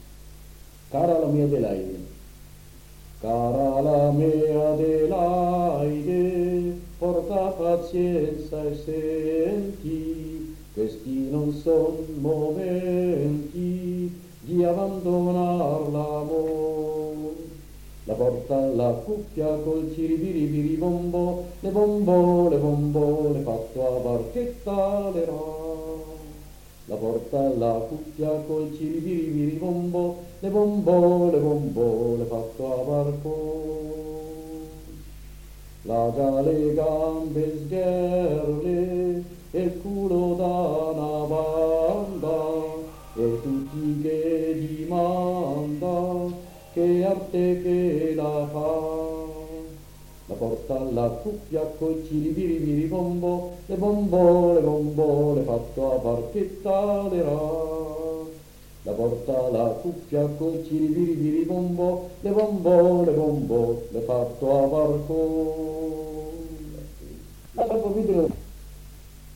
Registrazioni di canti popolari